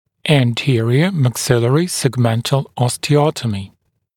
[æn’tɪərɪə mæk’sɪlərɪ səg’mentəl ˌɔstɪ’ɔtəmɪ][эн’тиэриэ мэк’силэри сэг’мэнтэл ˌости’отэми]сегментарная остеотомия переднего отдела верхней челюсти